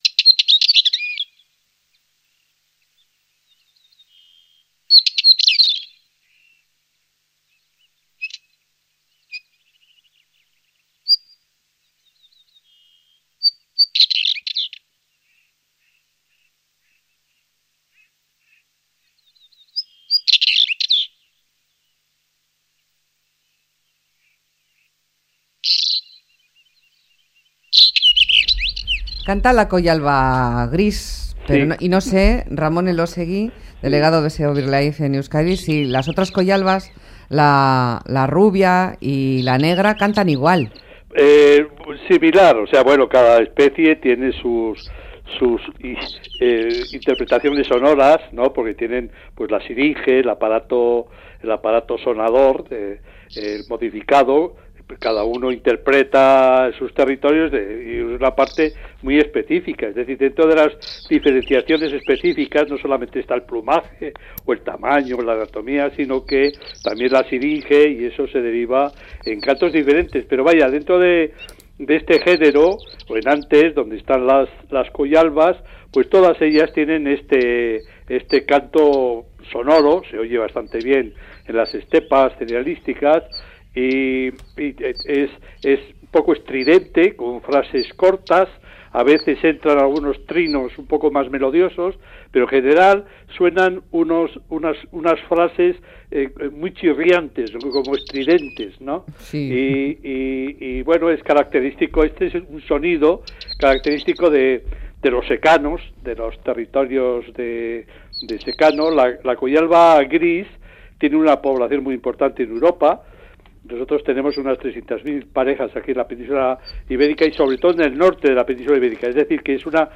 El canto es sonoro y agradable combinan frases ásperas con trinos más melodiosos. Son túrdidos muy esbeltos y de plumaje muy llamativo.